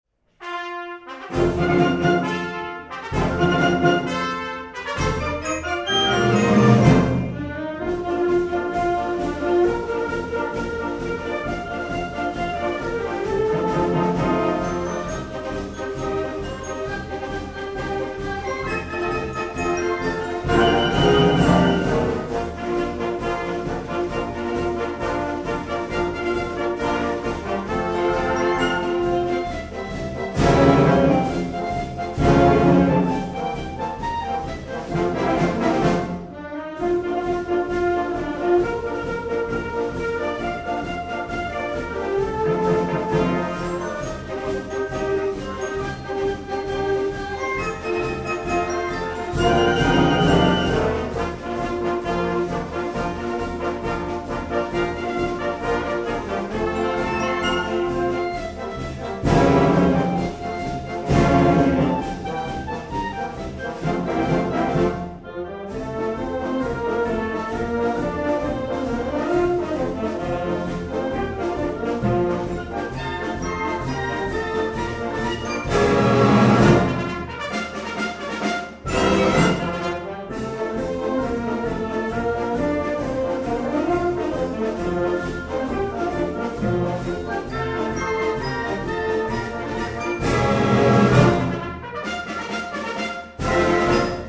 música académica costarricense